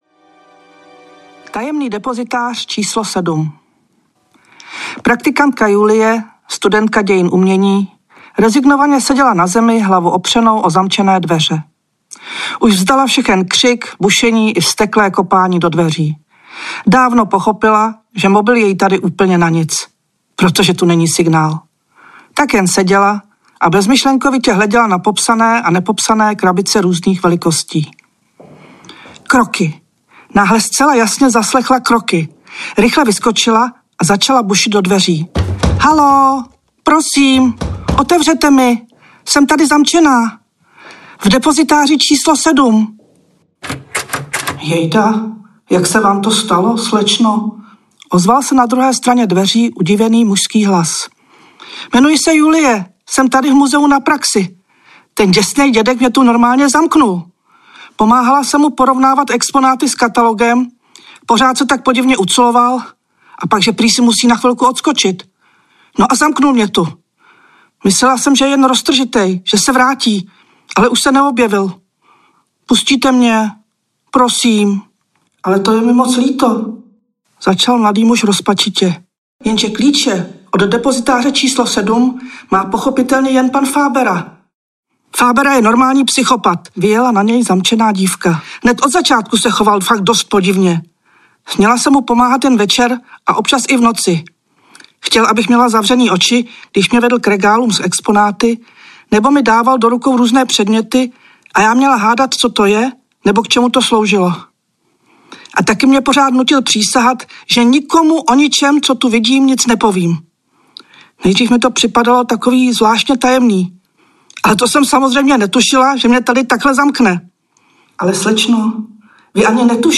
Vzkazy nahlas audiokniha
Ukázka z knihy
• InterpretRůzní interpreti